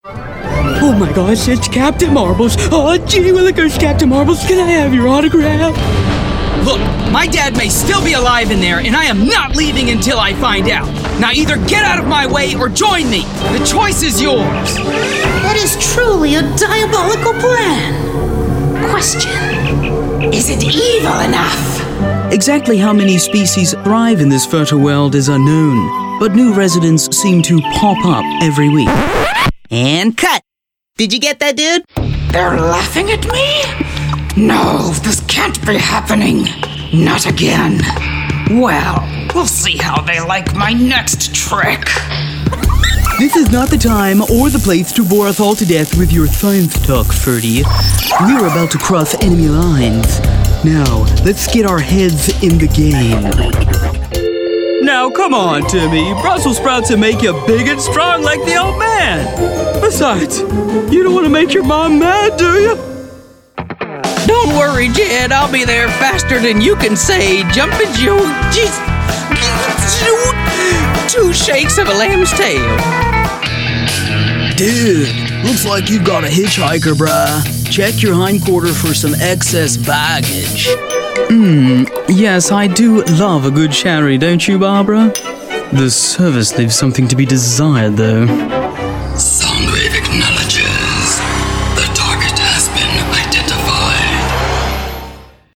Voice Samples: Animation
male